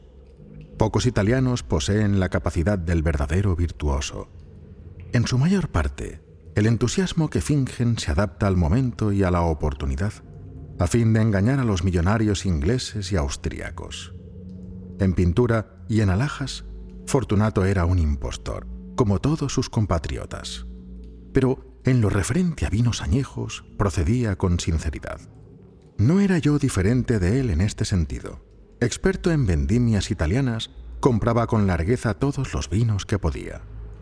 Erzählung
Mikrofon: Neumann TLM-103
Im mittleren Alter
Bass